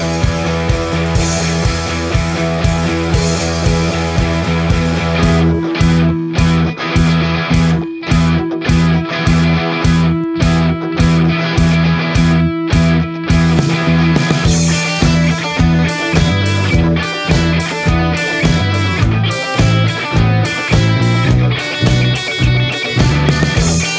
no Backing Vocals Indie / Alternative 3:56 Buy £1.50